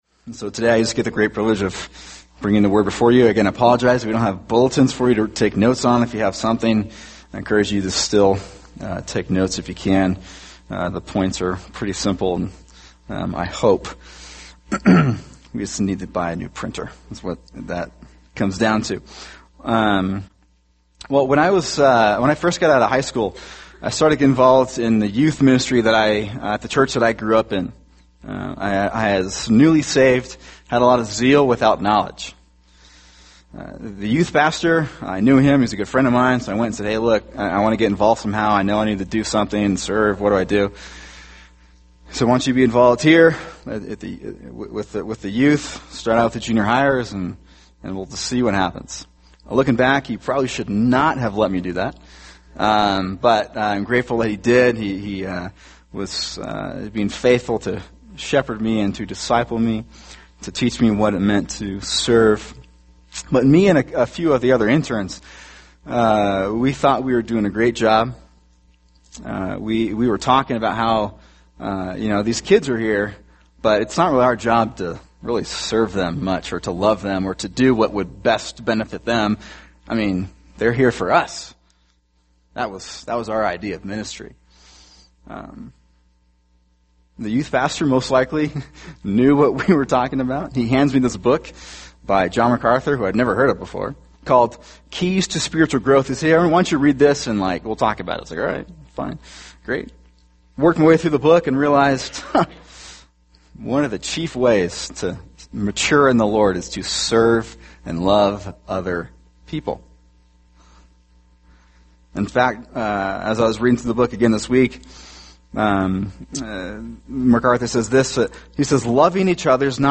[sermon] 1 Thessalonians 5:14-15 – Every Church Member’s Responsibility | Cornerstone Church - Jackson Hole